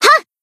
BA_V_Mine_Battle_Shout_1.ogg